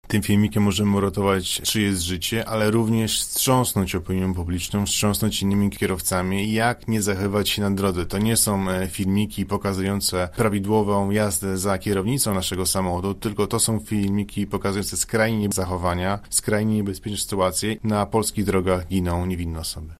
Filmy mają też wydźwięk prewencyjny i edukacyjny – mówią policjanci: